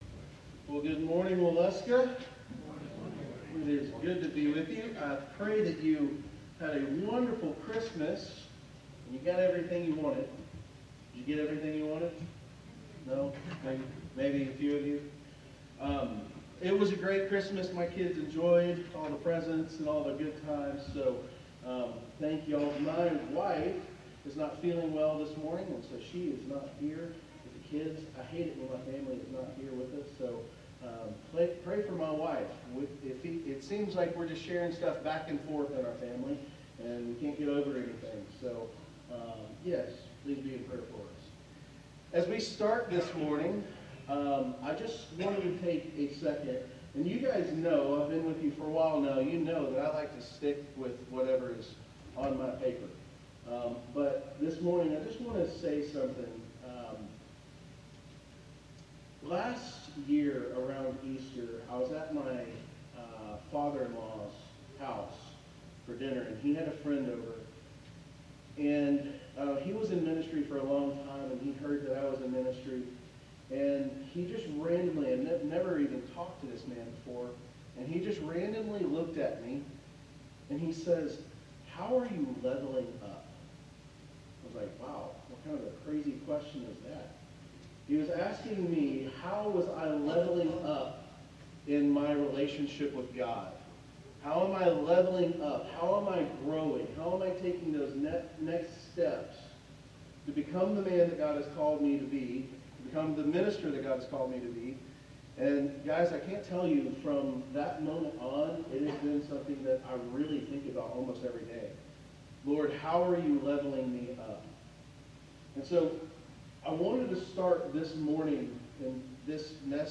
Sermons | Waleska First Baptist Church